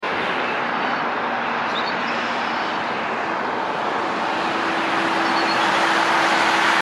Bald Eagle Vs. Blue Jay Sound Effects Free Download
Bald Eagle vs. Blue Jay